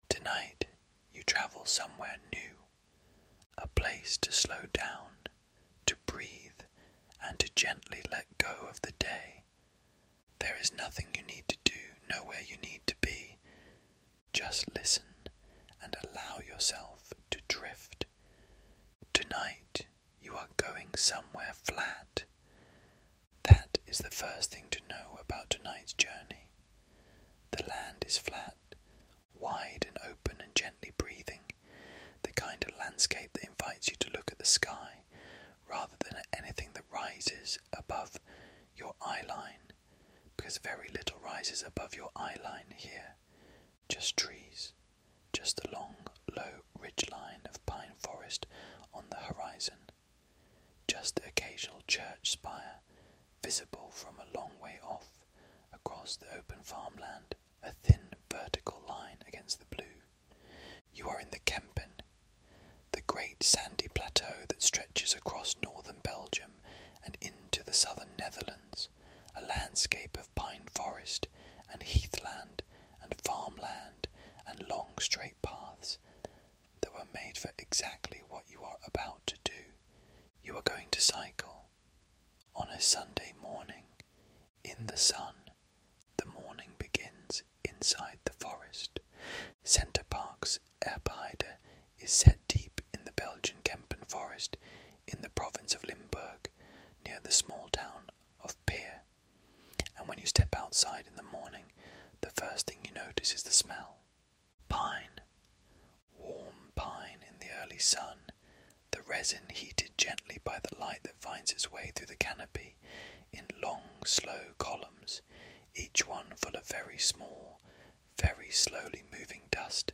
Calm Bedtime Stories for Deep Sleep